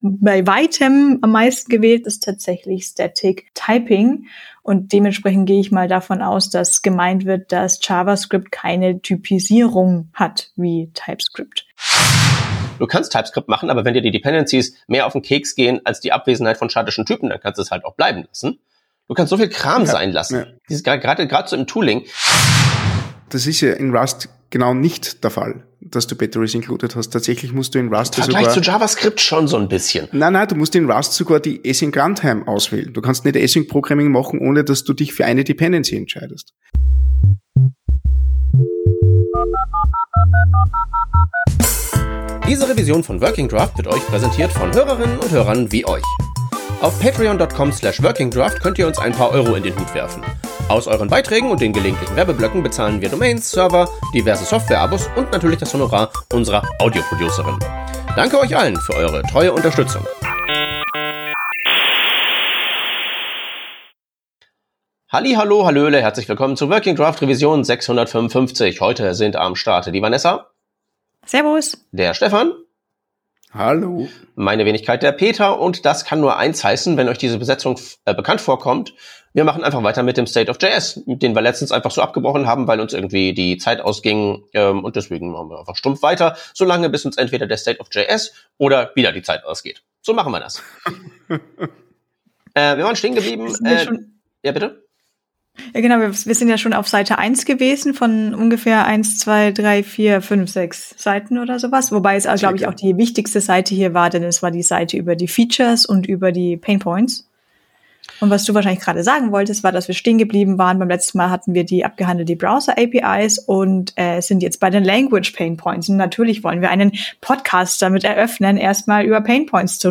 Revision 614: Zusammenarbeit in der Software-Entwicklung – eine Diskussionsrunde